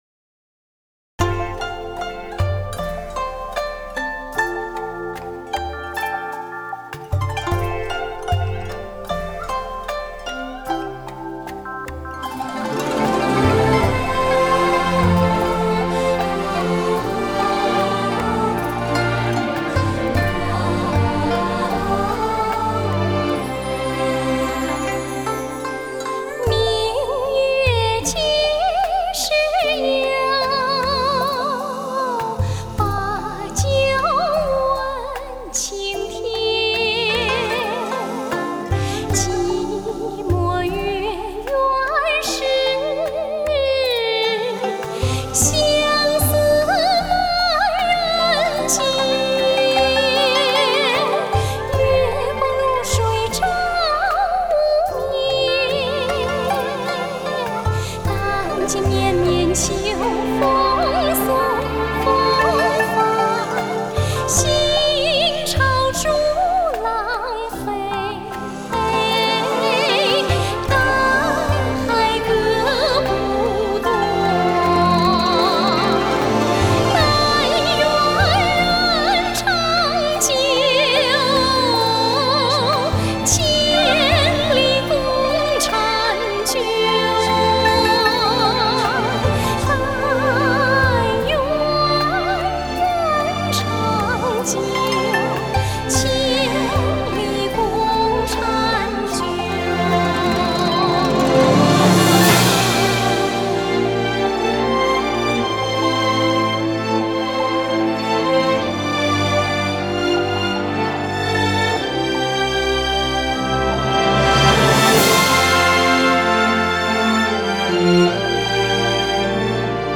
令人沉迷的全新民歌经典之作
这是一张民歌与流行音乐结合得天衣无缝的唱片，写下新民歌的神话。